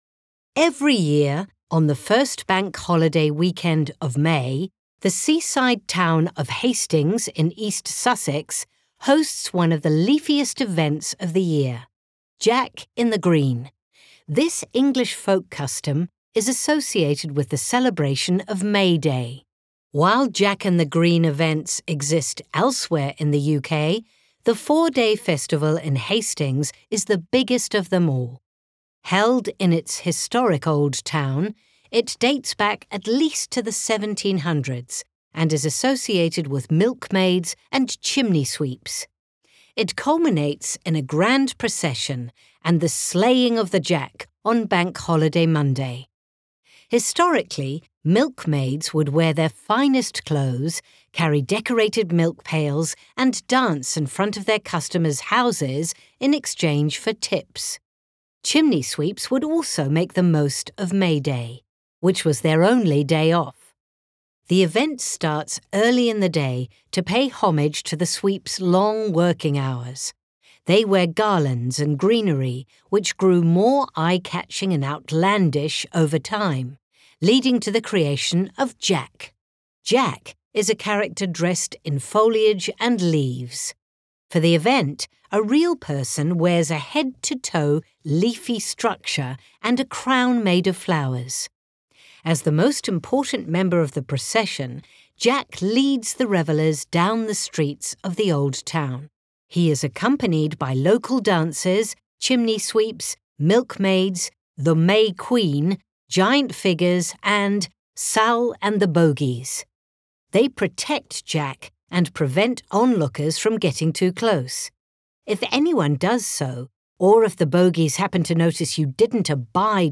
Speaker (UK accent)